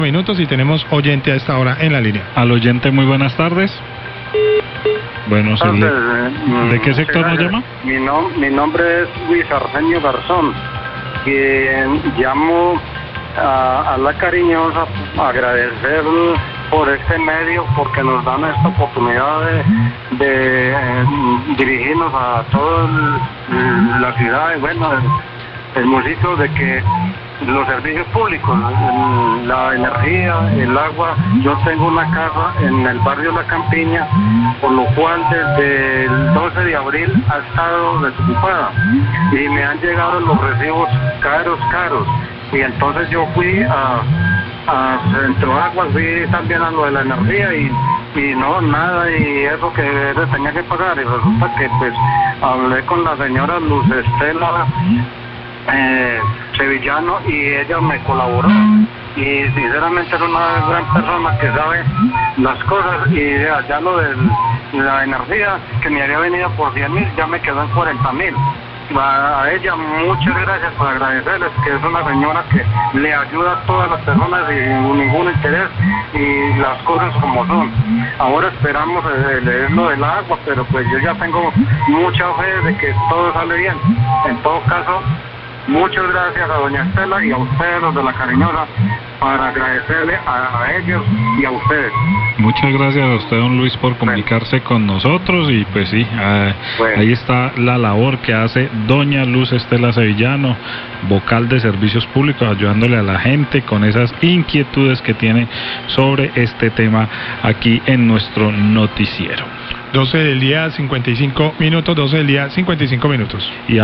Oyente agradece a vocal de control de servicios públicos por tramites para reducir el costo de recibos de energía, La Cariñosa 1255pm
Radio